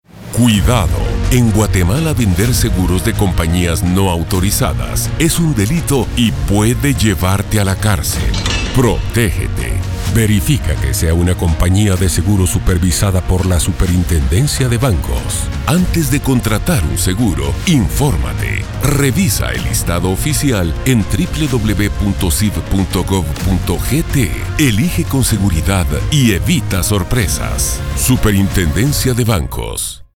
Anuncios en Radio